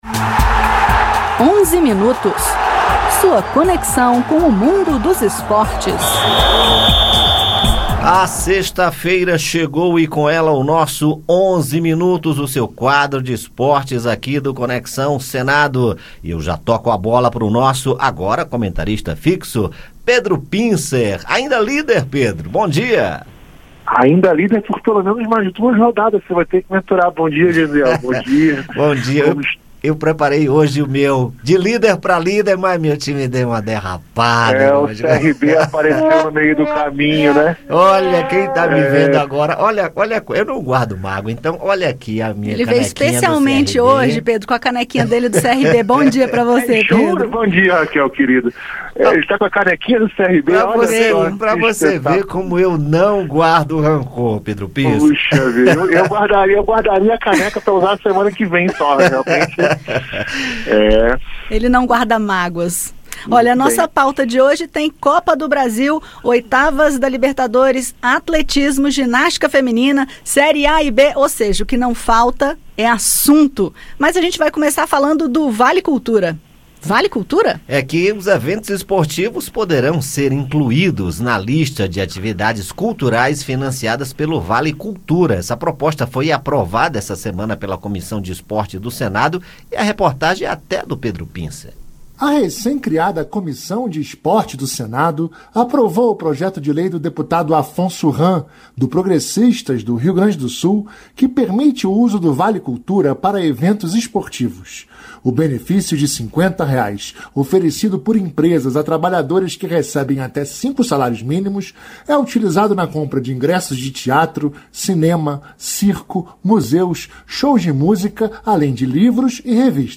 Na sequência, ouça nos comentários esportivos as últimas notícias sobre a Copa do Brasil, as oitavas da Libertadores, atletismo, ginástica feminina e muito mais.